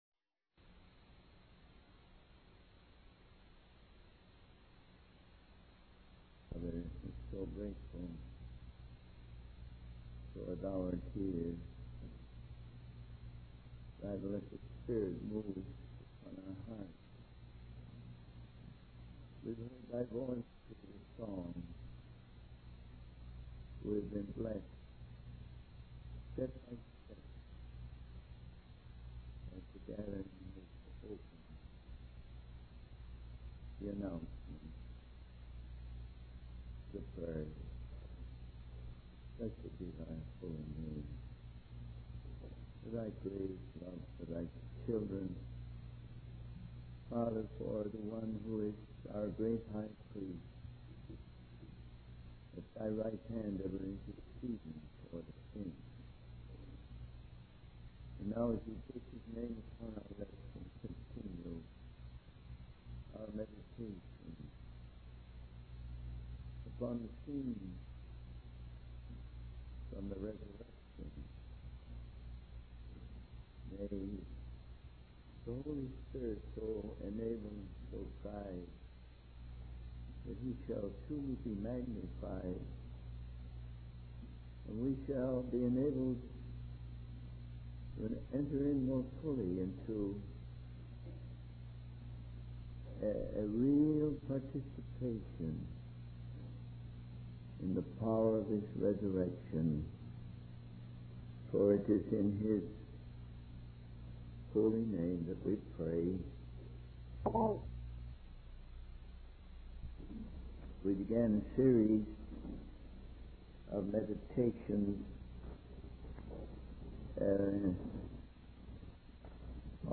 In this sermon, the speaker discusses the variations in the accounts of the resurrection of Jesus as recorded in the Gospel of John. He explains that these variations are not discrepancies but rather the natural result of multiple witnesses giving their own perspectives.